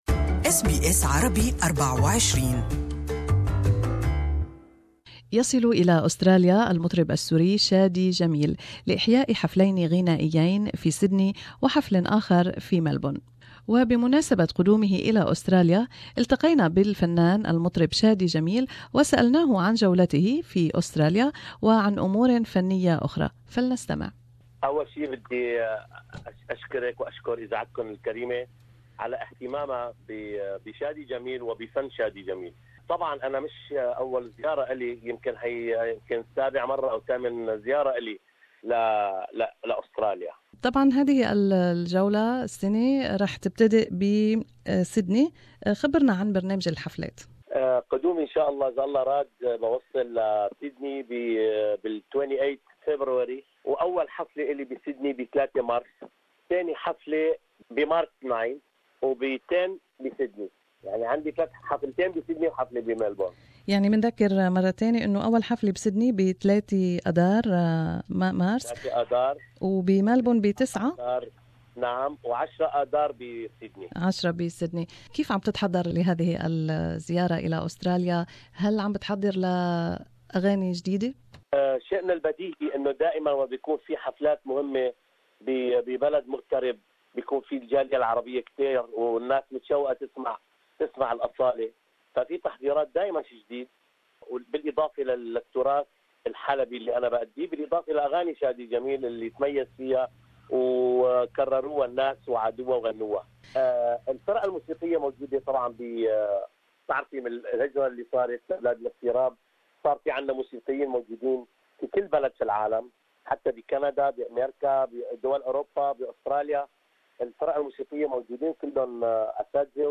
Interview with singer Shadi Jamil before his visit to Australia